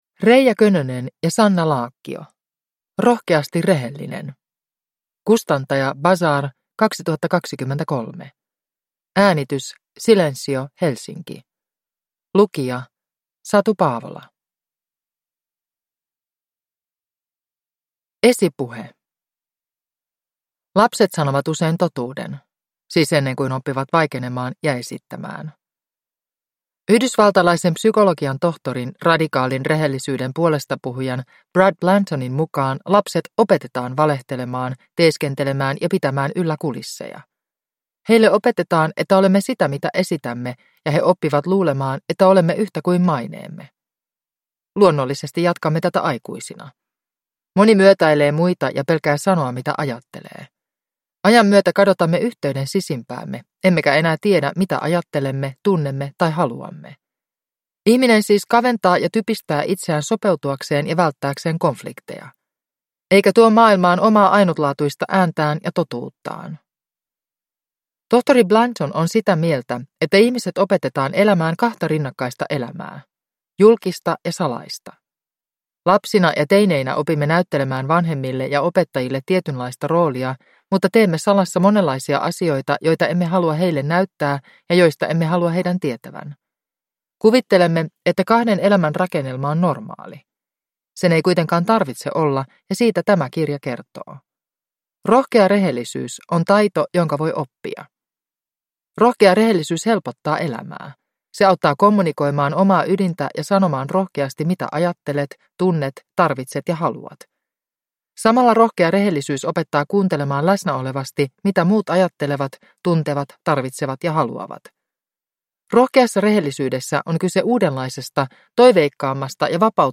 Rohkeasti rehellinen – Ljudbok – Laddas ner